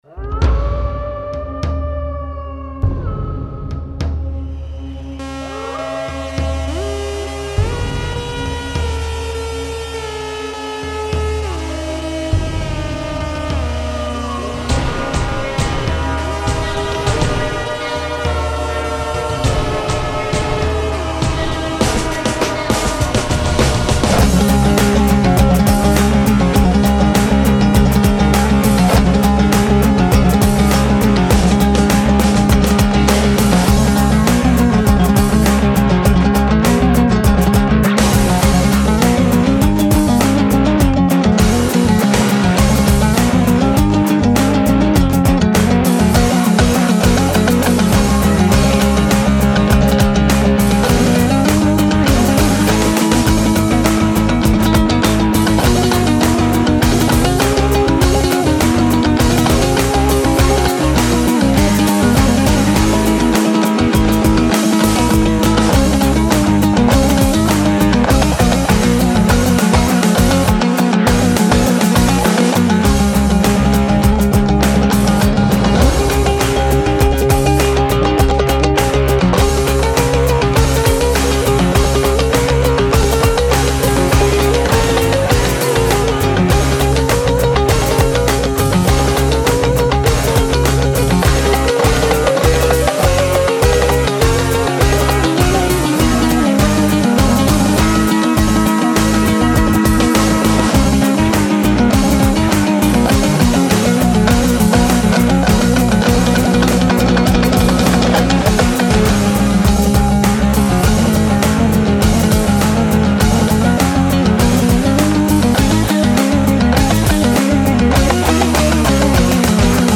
это инструментальная композиция